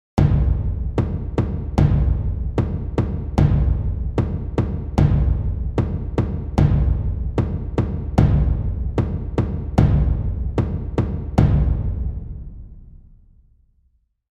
Dramatic Tribal Drum Timpani Sound Effect
Description: Dramatic tribal drum timpani sound effect.
Perfect for films, games, TikTok or YouTube reels, trailers, and cinematic videos, it delivers powerful, deep percussion that commands attention. Use this epic tribal timpani audio to create tension, excitement, and a cinematic atmosphere in your content.
Dramatic-tribal-timpani-drum-effect.mp3